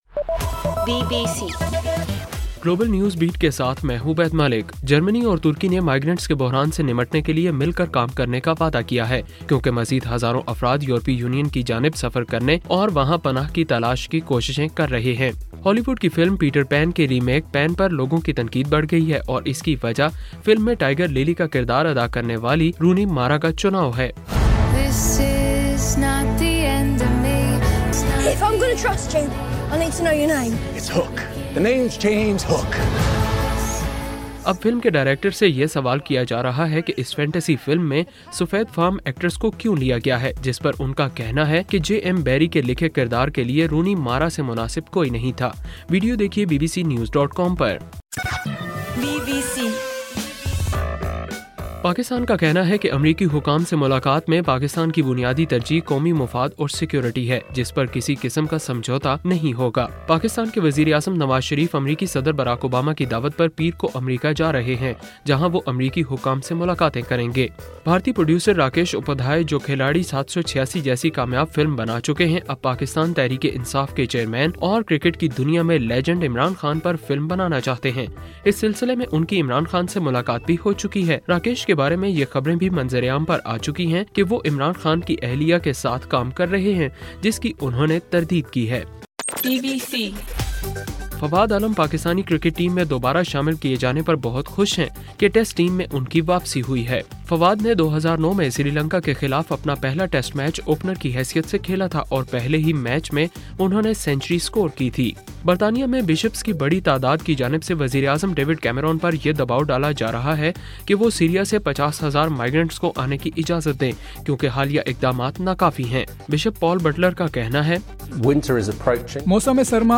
اکتوبر 18: رات 9 بجے کا گلوبل نیوز بیٹ بُلیٹن